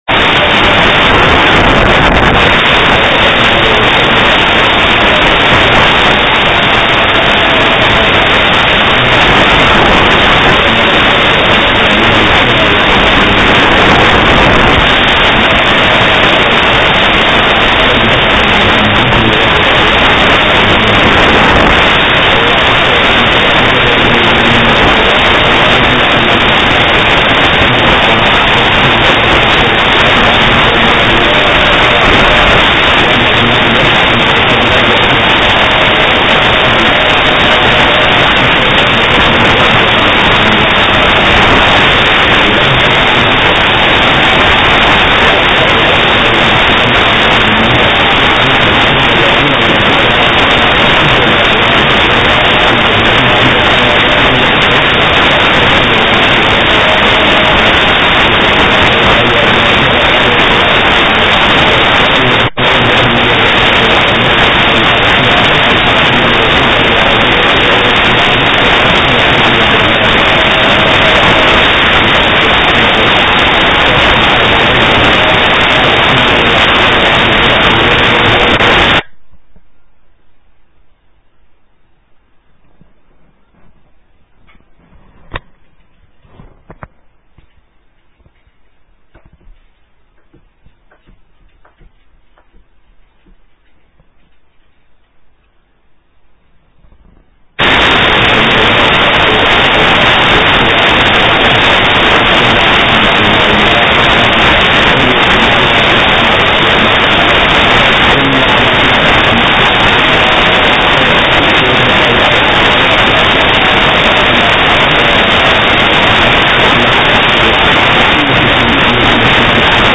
Muxaadaro